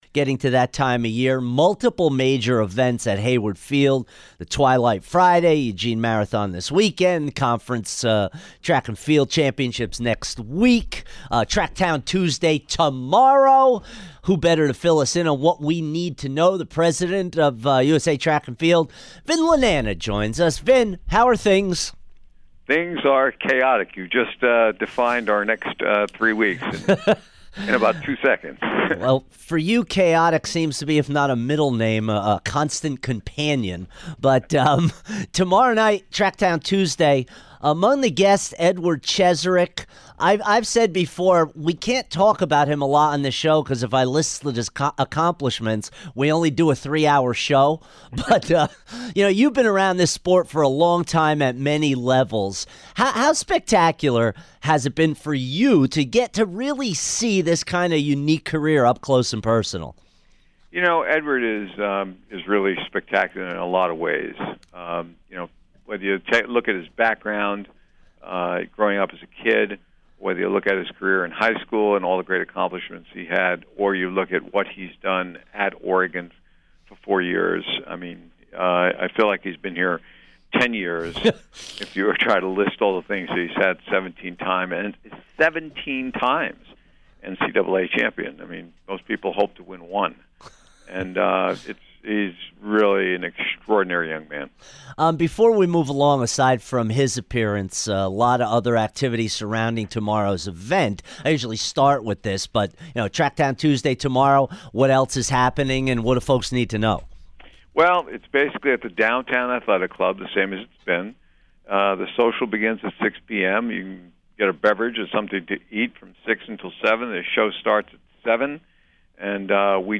Vin Lananna Interview 5-1-17